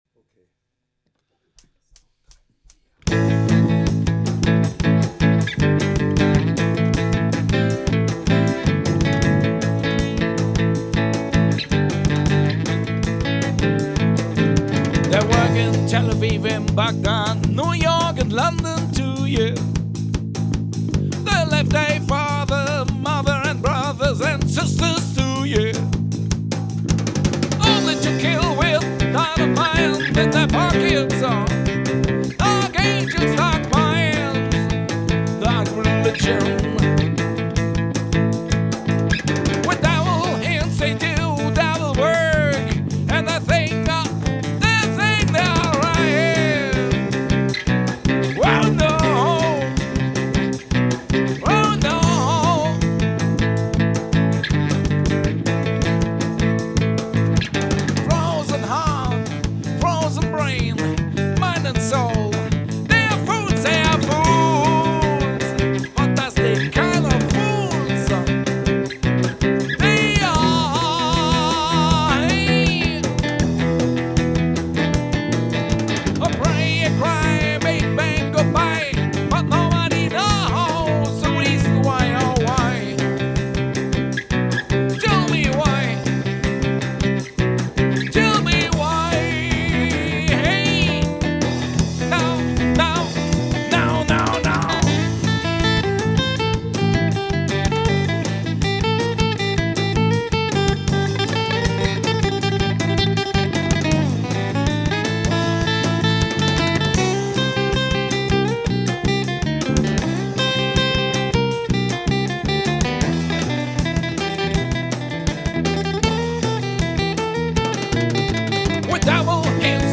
Unplugged Set - live aufgenommen im Studio